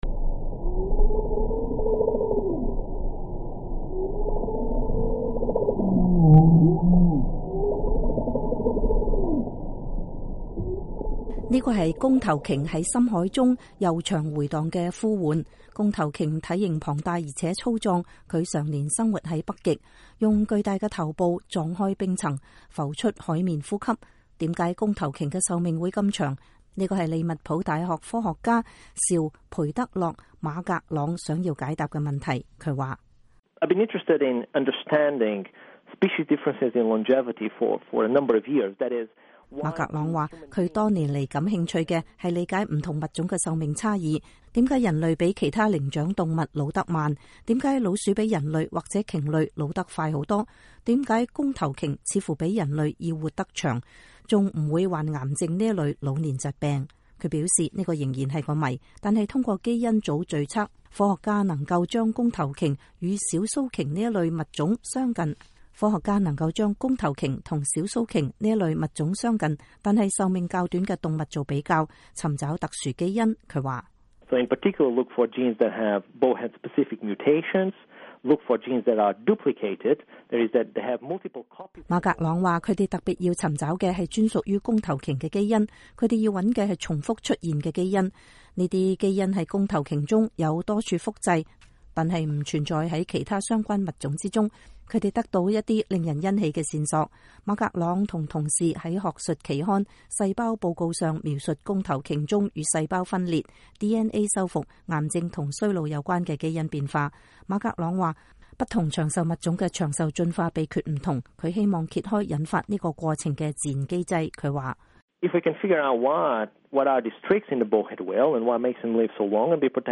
根據一項描述了弓頭鯨完整基因組的最新研究，弓頭鯨的基因也許藏有長壽奧秘。這是弓頭鯨在深海中那悠長回盪的呼喚。